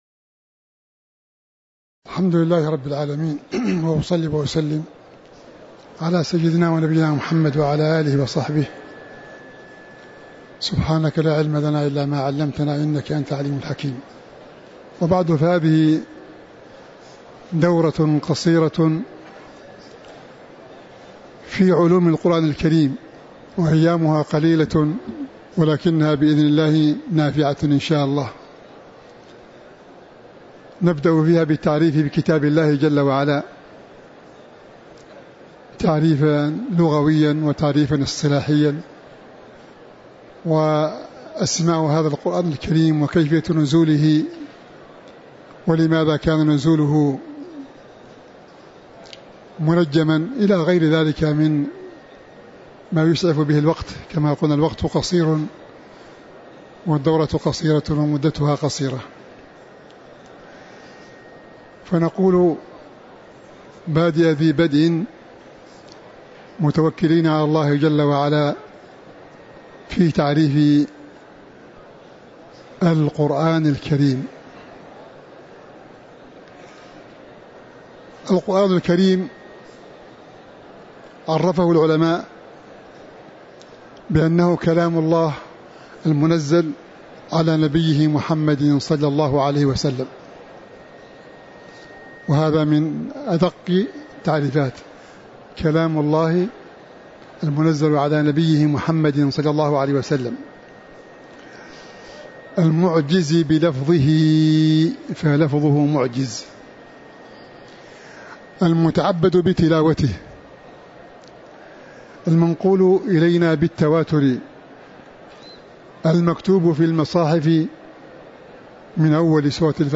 تاريخ النشر ١١ محرم ١٤٤٥ هـ المكان: المسجد النبوي الشيخ